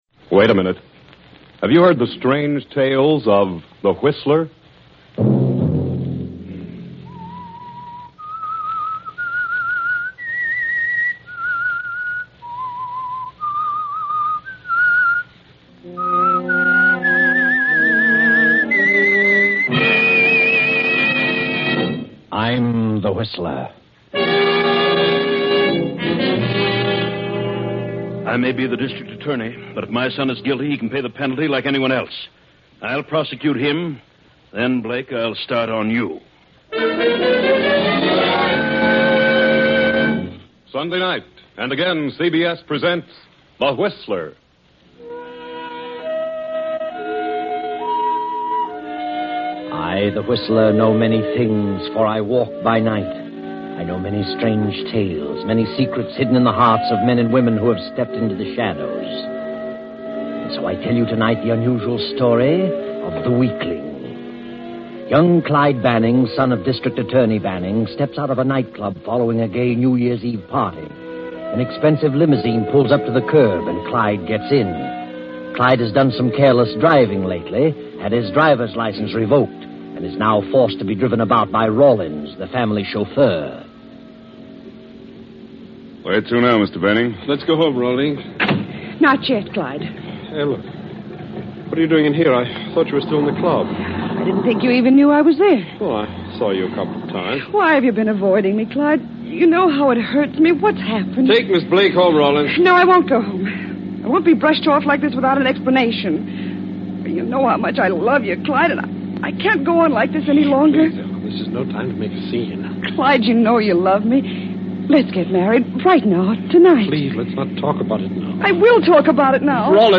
Can a District Attorney prosecute his own son? Secrets, crime, and suspense in classic old-time radio style.